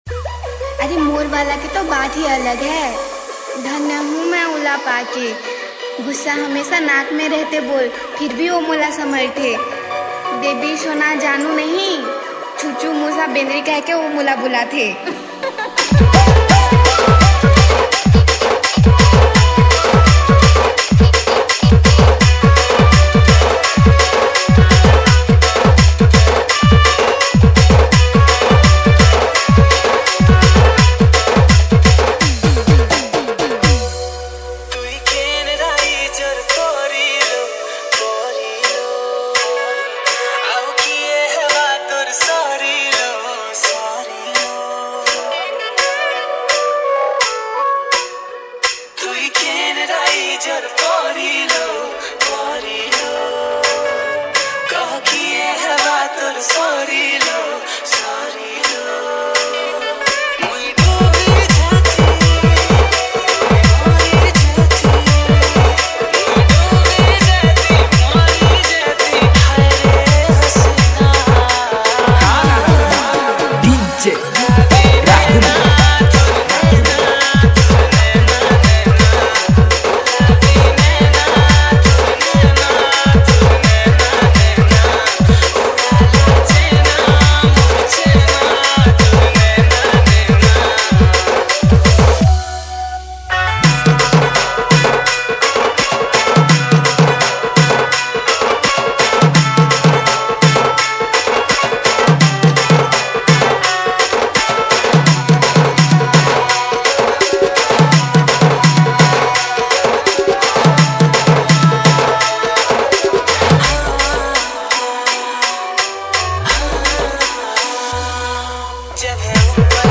SAMBALPURI LOVE DJ REMIX